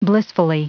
Prononciation du mot blissfully en anglais (fichier audio)
Prononciation du mot : blissfully